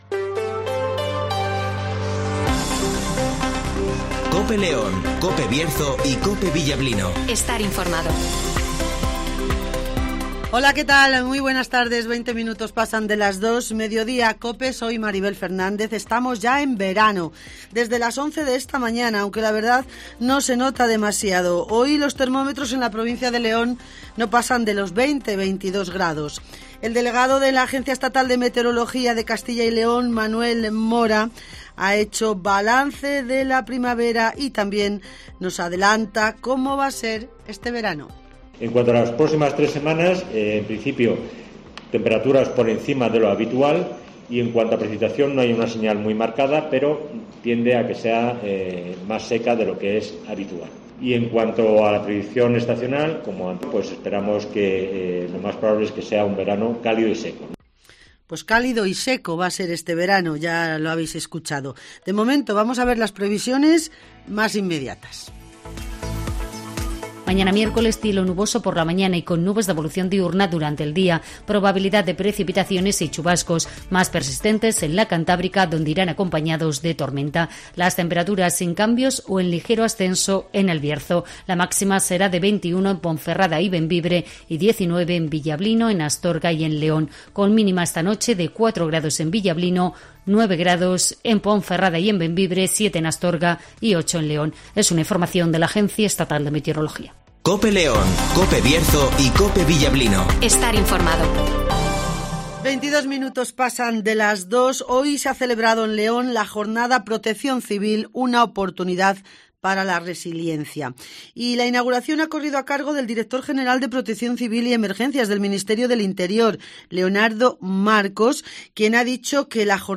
- José Antonio Díez ( Alcalde de León )